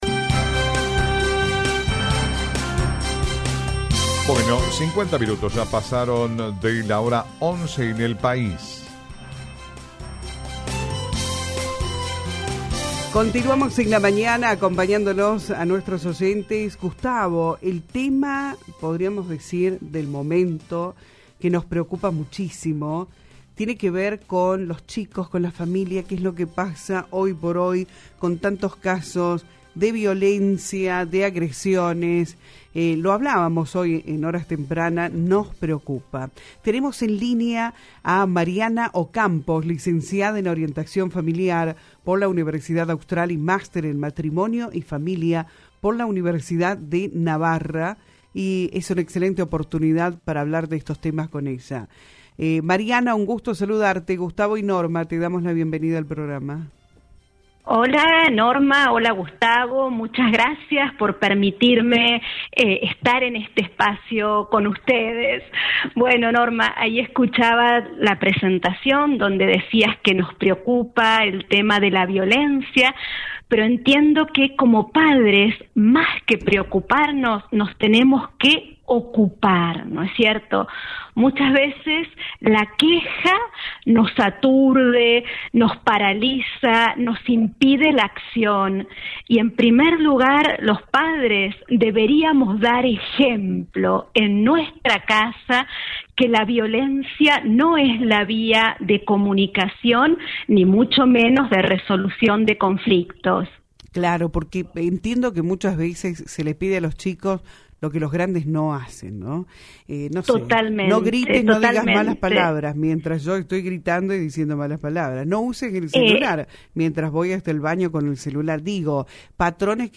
En una entrevista exclusiva para “Viva La Mañana”